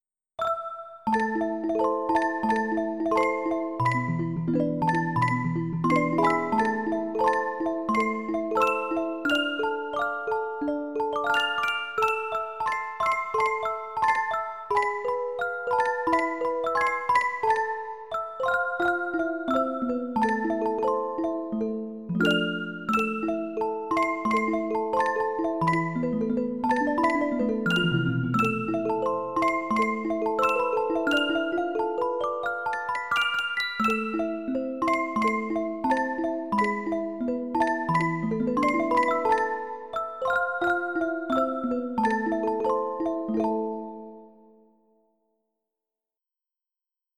Dark arrange version of famous works
folk song Scotland